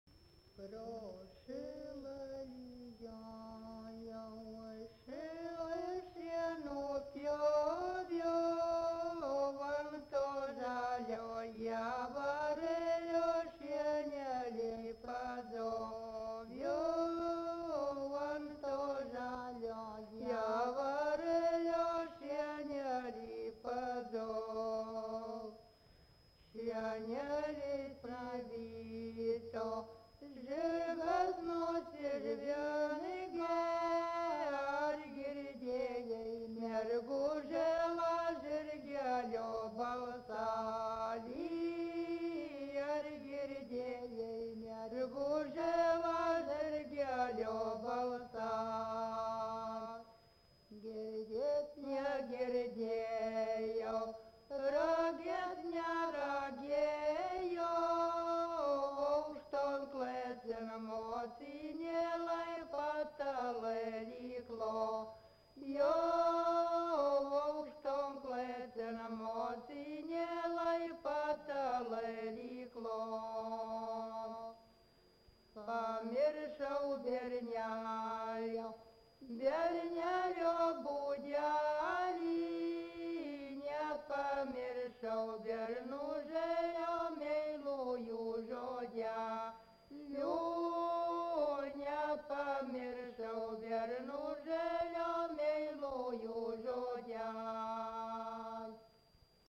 Subject daina
Erdvinė aprėptis Liškiava
Atlikimo pubūdis vokalinis